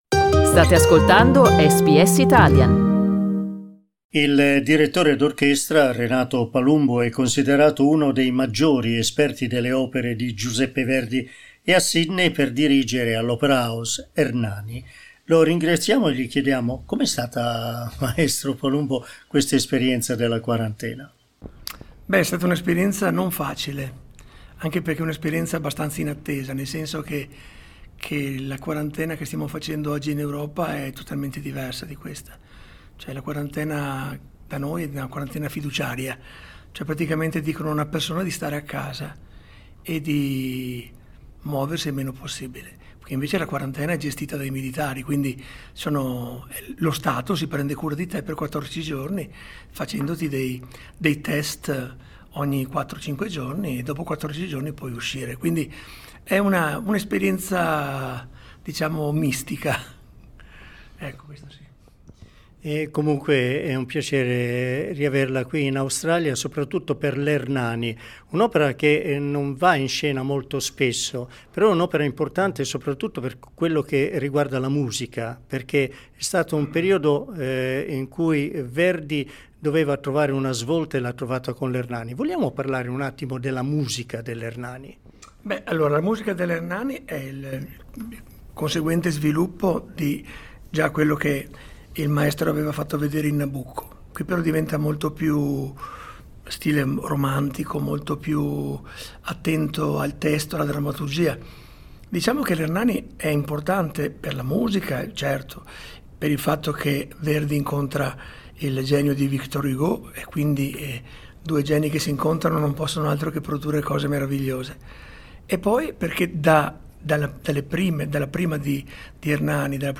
Riascolta qui l'intervista con Renato Palumbo: SKIP ADVERTISEMENT LISTEN TO Renato Palumbo dirige "Ernani" all'Opera House di Sydney SBS Italian 14:21 Italian Le persone in Australia devono stare ad almeno 1,5 metri di distanza dagli altri.